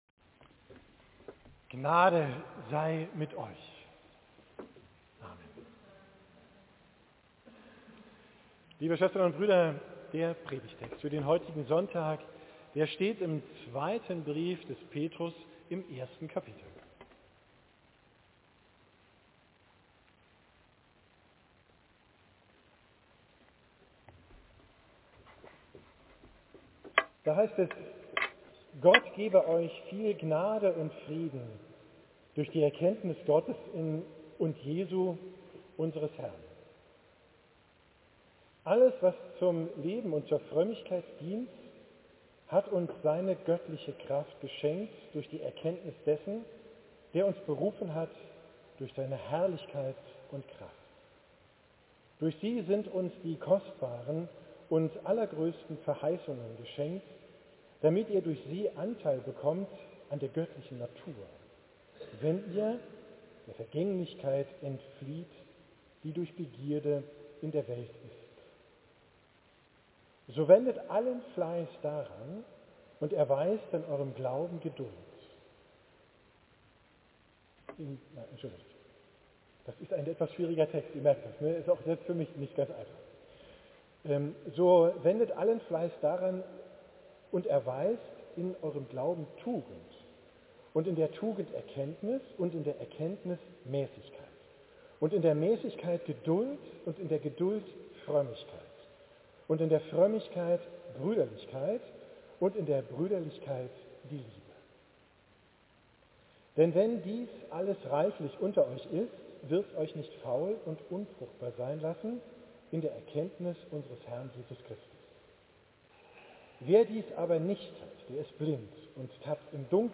Predigt vom Sonntag 18. II 2024 mit dem Proprium vom Aschermittwoch